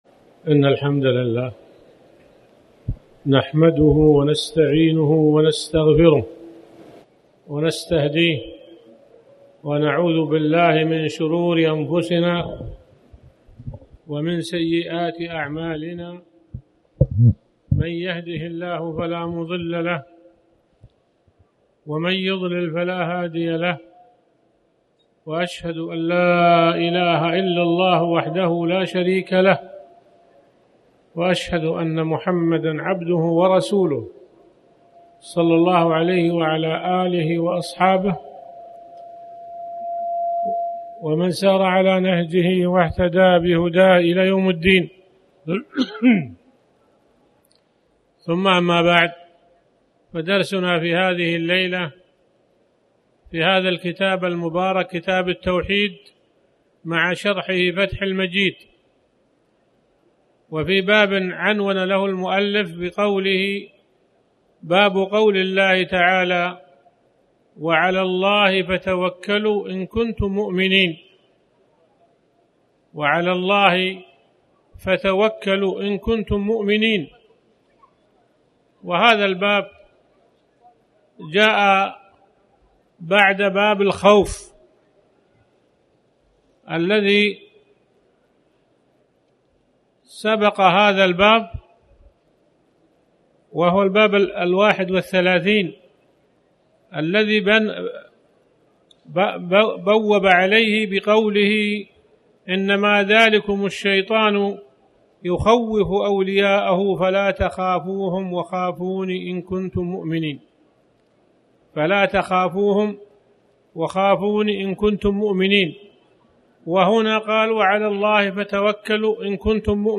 تاريخ النشر ٢٠ محرم ١٤٣٩ هـ المكان: المسجد الحرام الشيخ